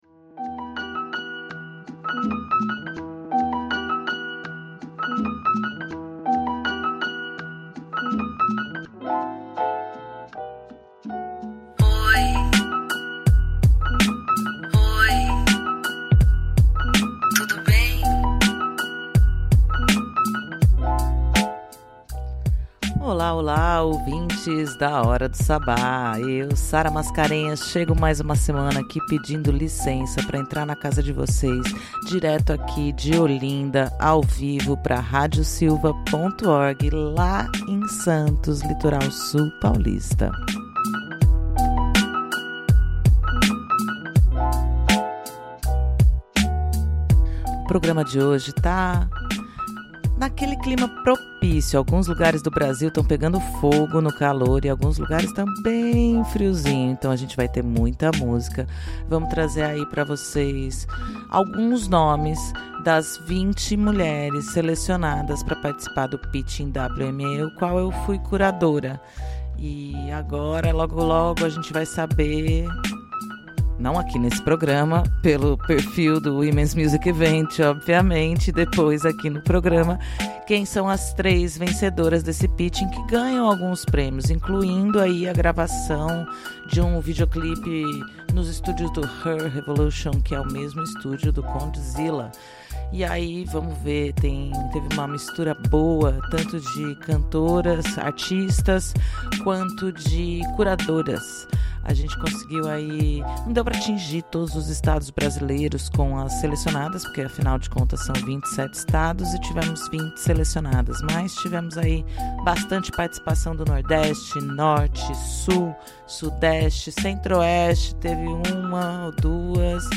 Na curadoria musical desta edição, vamos tocar algumas das 20 mulheres selecionadas para o Pitching WME Conference.